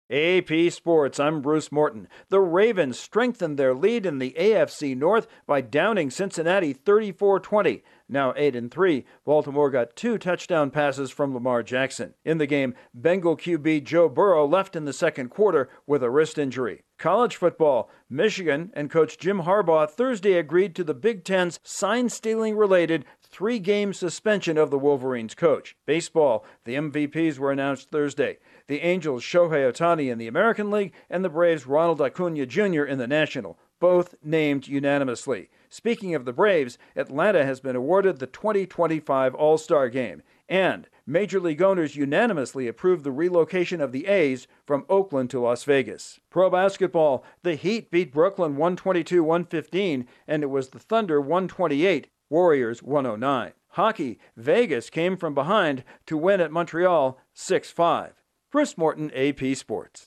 The Ravens turn back the Bengals, Michigan football coach Jim Harbaugh agrees to a three-game suspension, baseball names its MVPs, awards the 2025 All-Star Game and approves the relocation of the A's. Correspondent